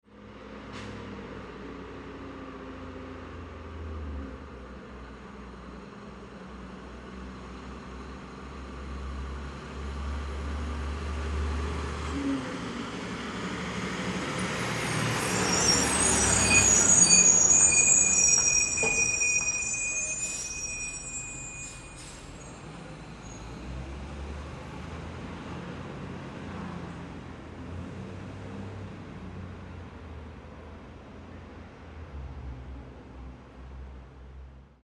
Звуки мусоровоза
Скрип тормозов мусоровоза при остановке